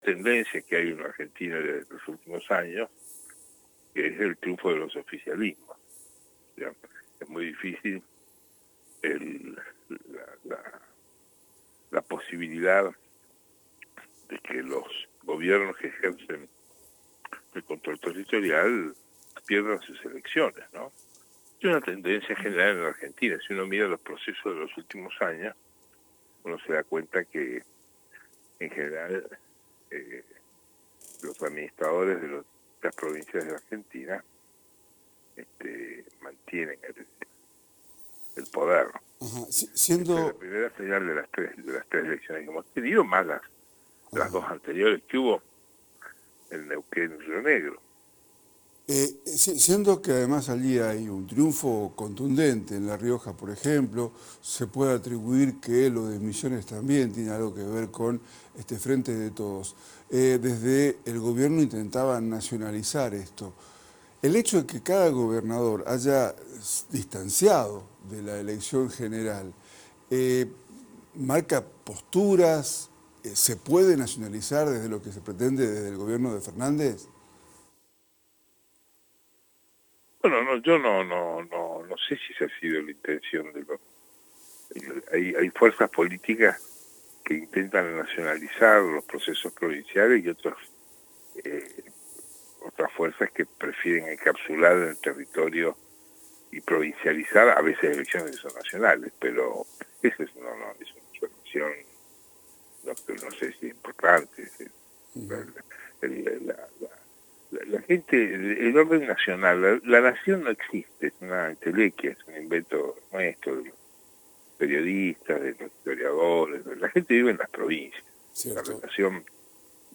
ELECCIONES QUE NI SORPENDEN NI DEFRAUDAN (PAÍS NORMAL) (Un diálogo por radio)